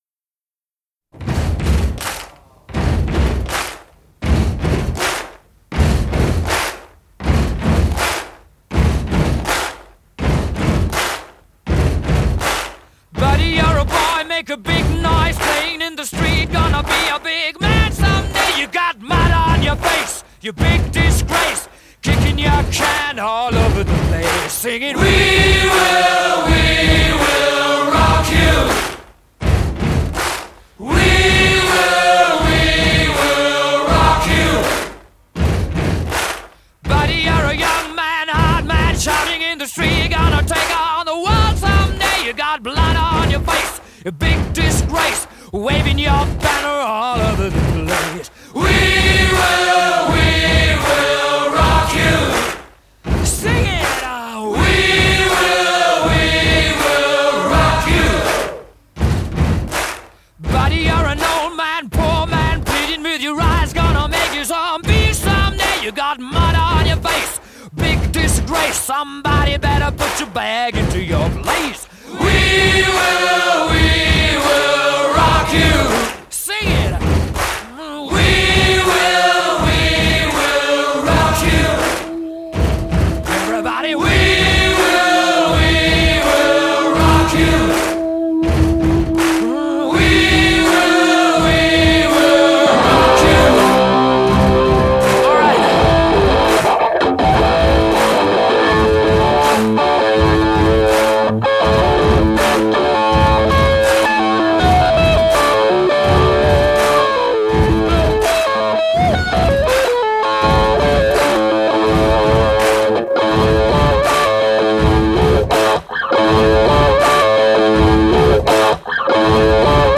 Classic Rock, Arena Rock, Hard Rock